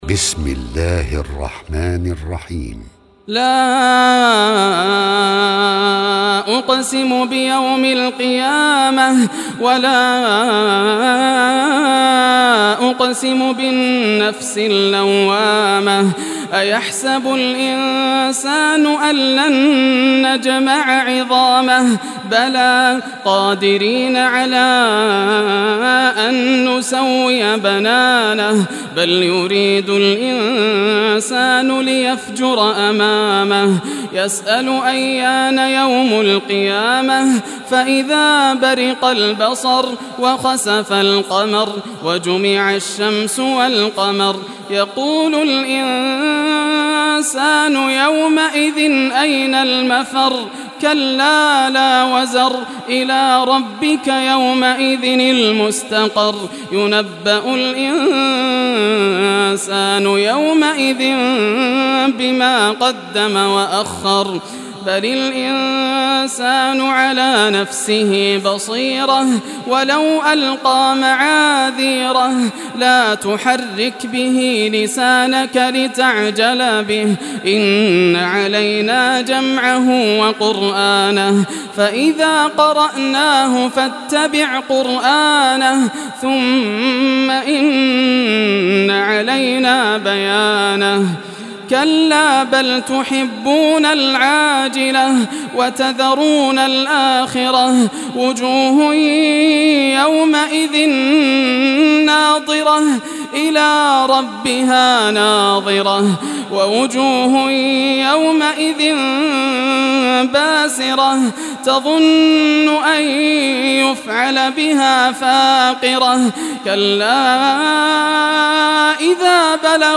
Surah Al-Qiyamah Recitation by Yasser al Dosari
Surah Al-Qiyamah, listen or play online mp3 tilawat / recitation in Arabic in the beautiful voice of Sheikh Yasser al Dosari.